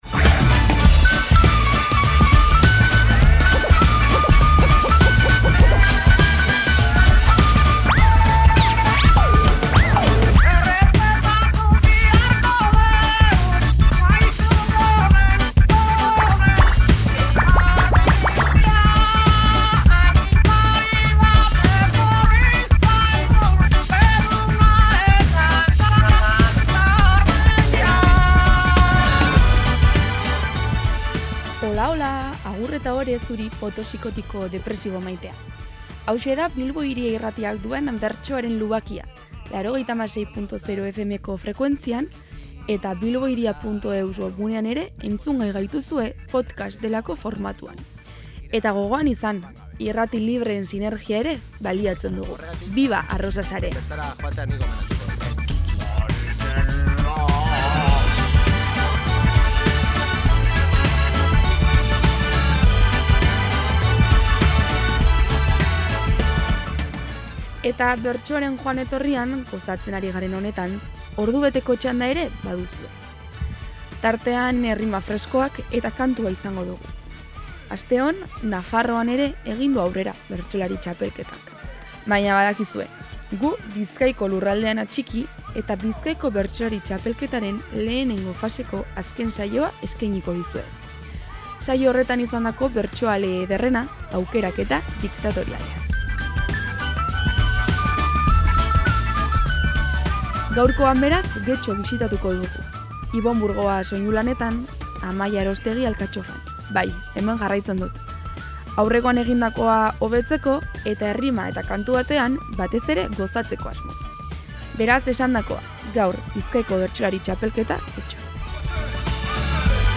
Bizkaiko Bertsolari Txapelketako bozgarren eta azken saioa izan da Getxon, eta bertsoen aukeraketa diktatoriala duzue entzungai gaurko saioan.